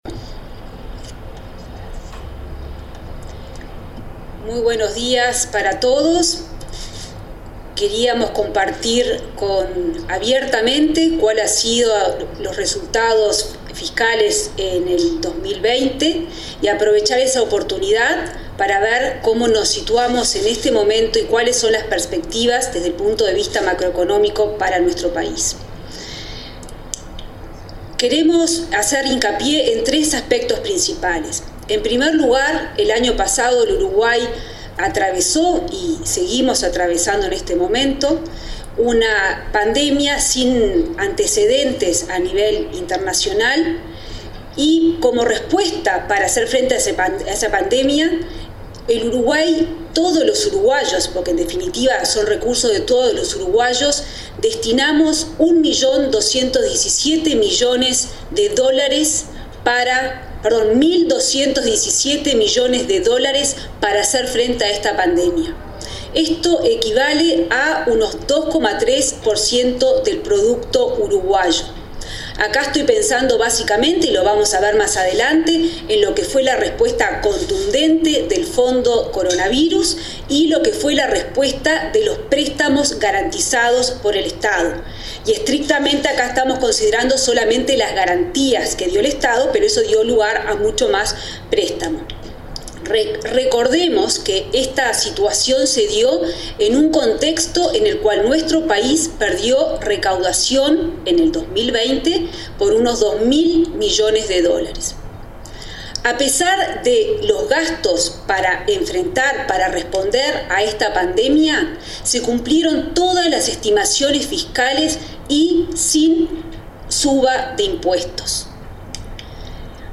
Videoconferencia de Azucena Arbeleche sobre balance económico de 2020 y presentación de perspectivas macroeconómicas para 2021